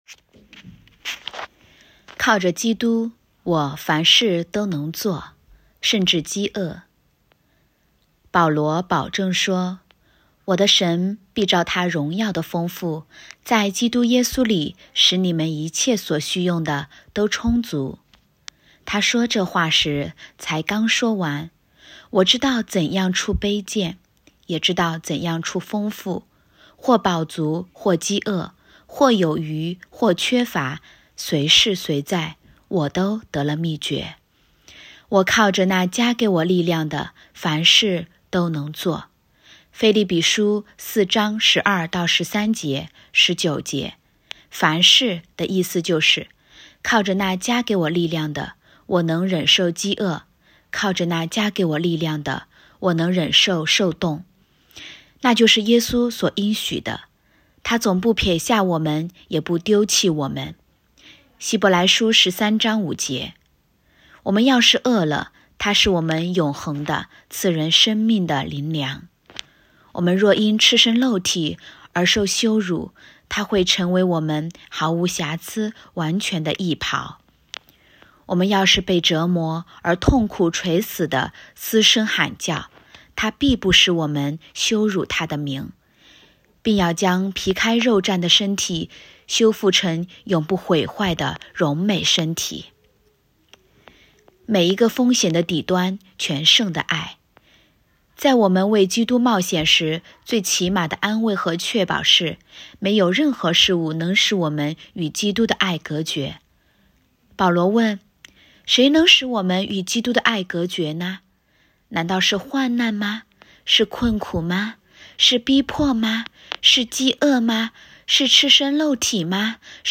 2024年3月21日 “伴你读书”，正在为您朗读：《活出热情》 欢迎点击下方音频聆听朗读内容 https